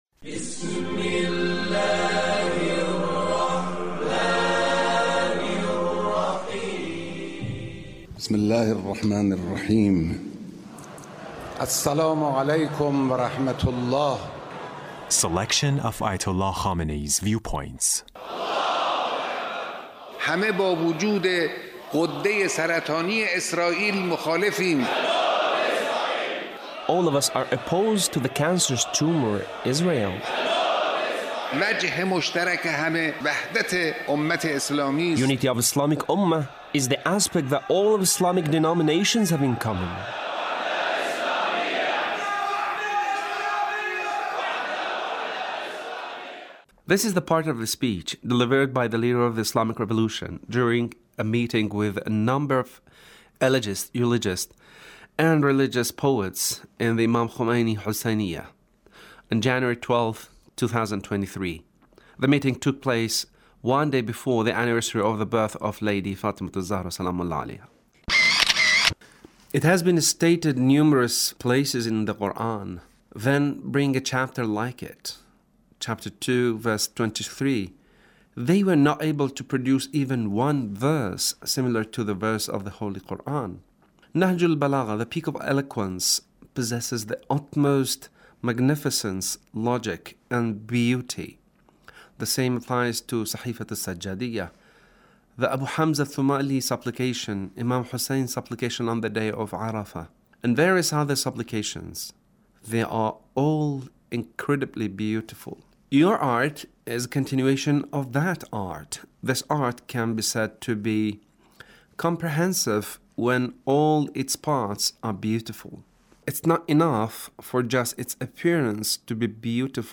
Leader's Speech (1622)